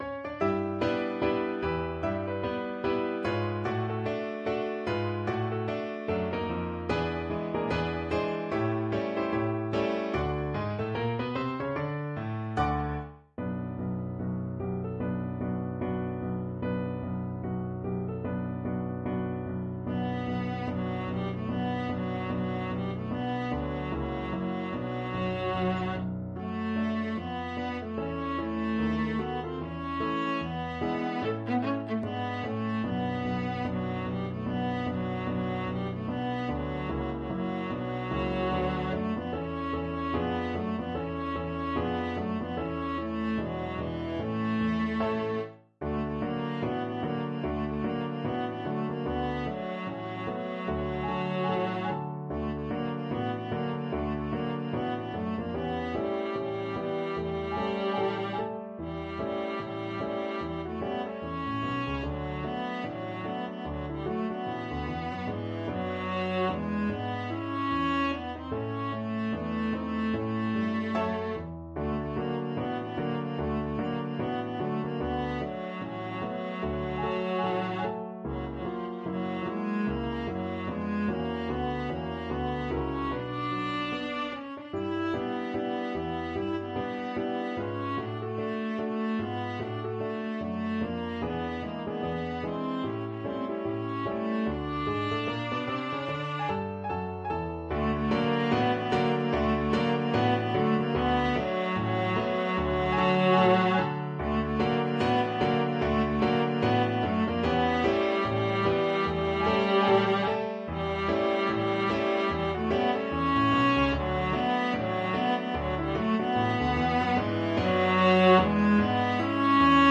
2/2 (View more 2/2 Music)
Moderato = c. 74
Arrangement for Viola and Piano
Pop (View more Pop Viola Music)